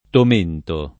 [ tom % nto ]